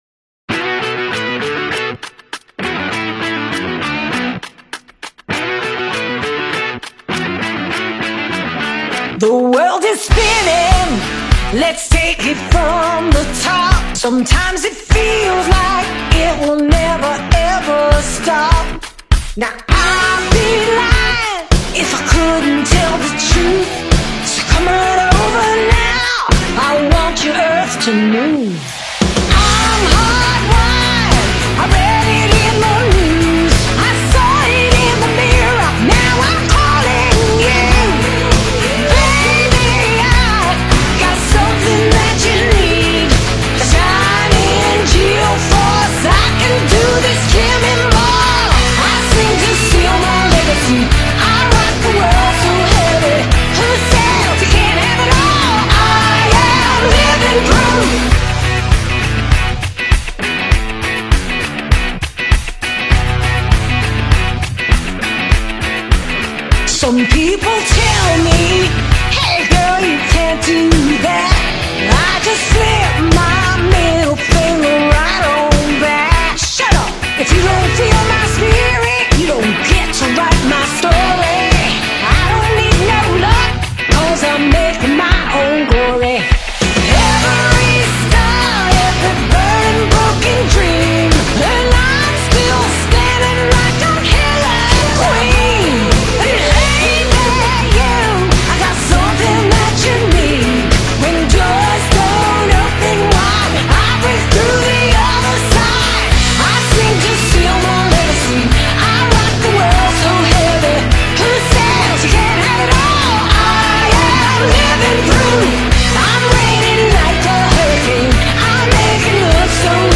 There is a throwback feel here, but it does not feel dated.